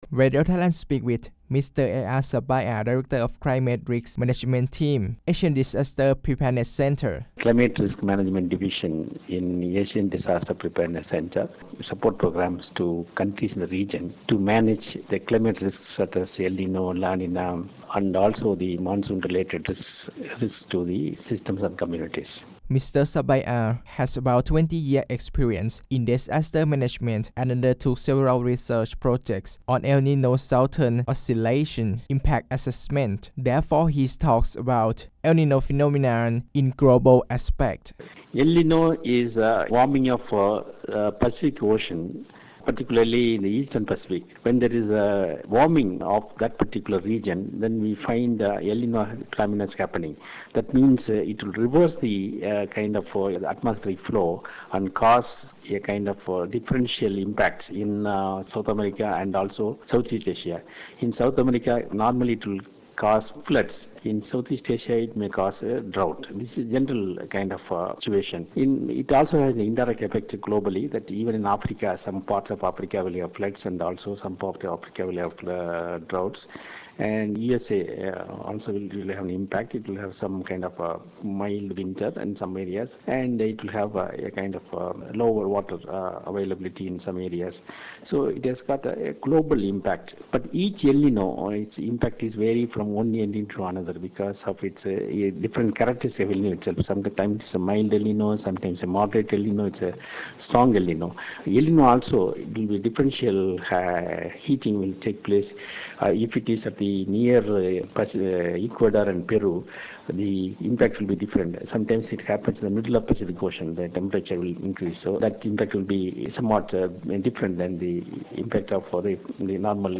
The recorded interview was aired through FM.88